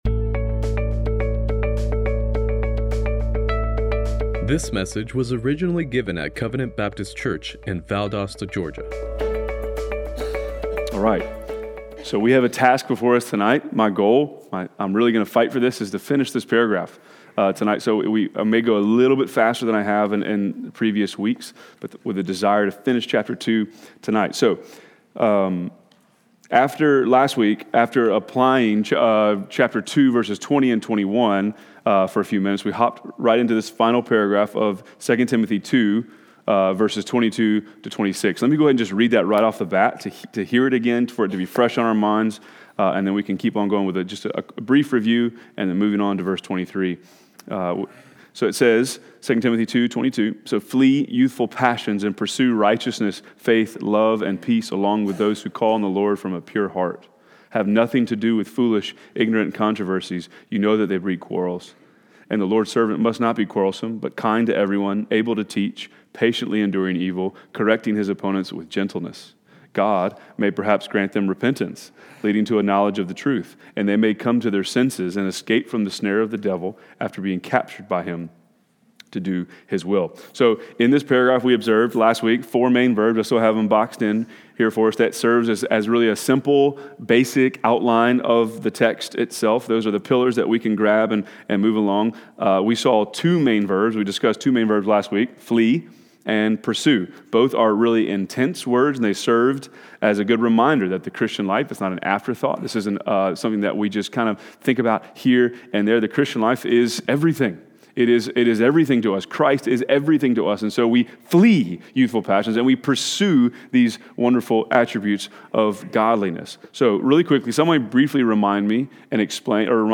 Inductive Bible Study :: 2 Timothy 2:22-26 — Covenant Baptist Church | Valdosta, GA